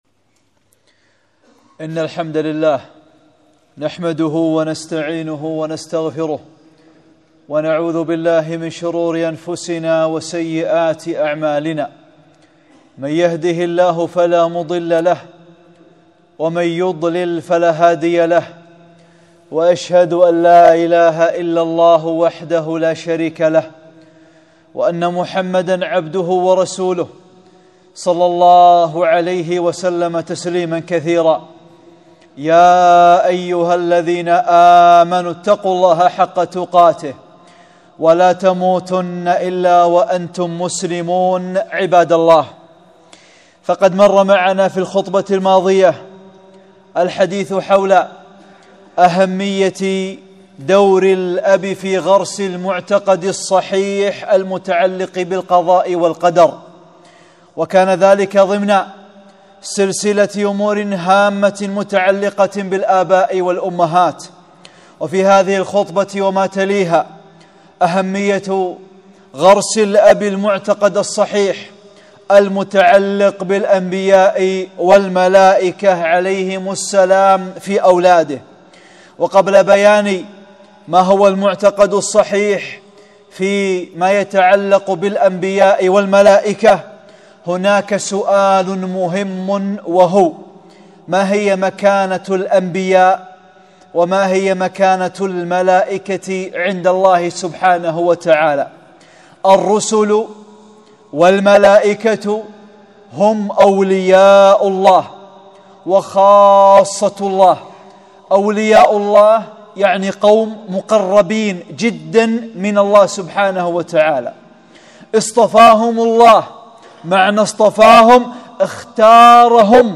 (33) خطبة - دور الأب في غرس مكانة الأنبياء في الأولاد